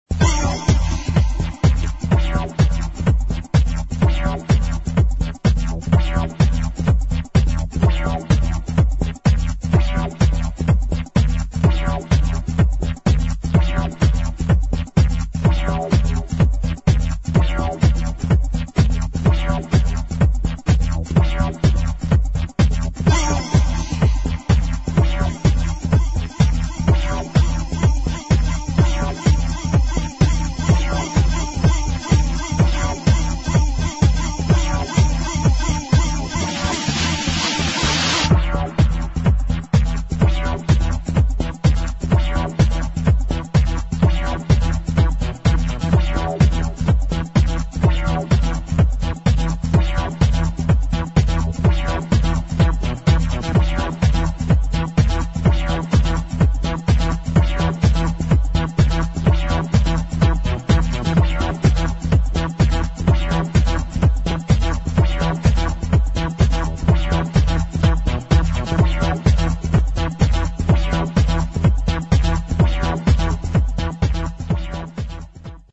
[ HOUSE ]